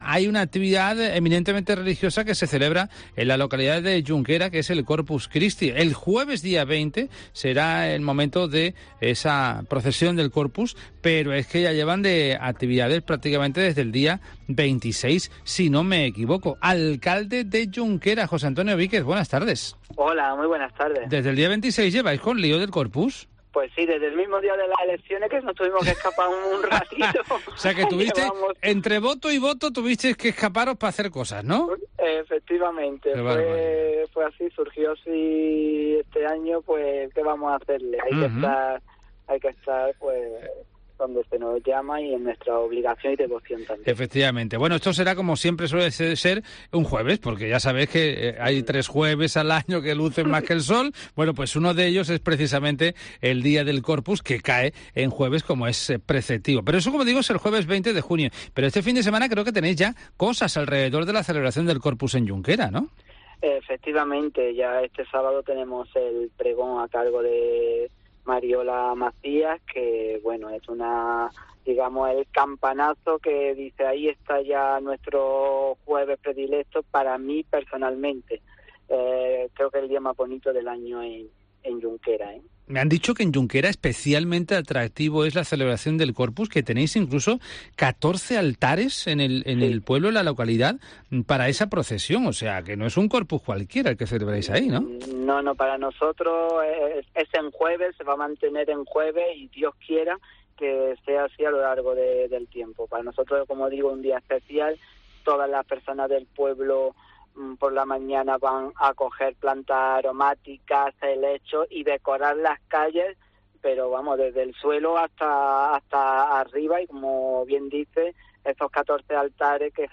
Todos los deatalles de esta celebarción nos o cuante el alcalde de la localidad Josá Antonio Víquez.